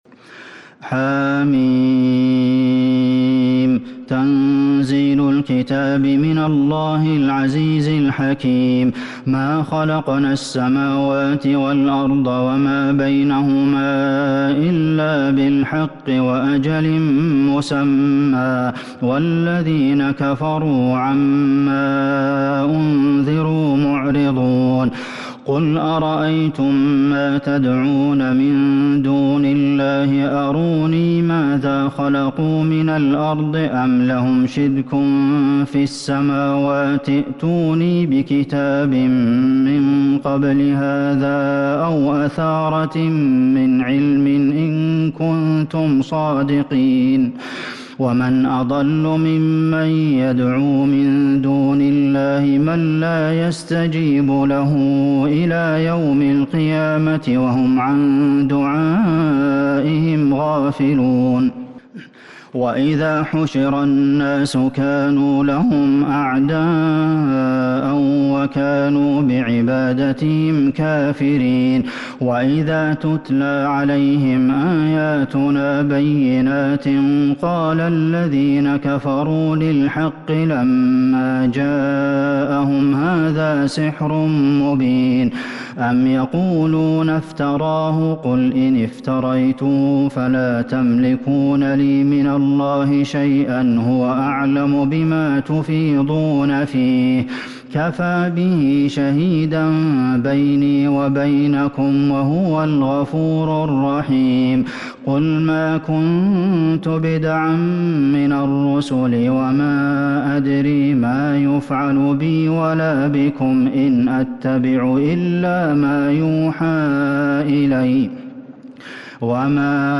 سورة الأحقاف Surat Al-Ahqaf من تراويح المسجد النبوي 1442هـ > مصحف تراويح الحرم النبوي عام 1442هـ > المصحف - تلاوات الحرمين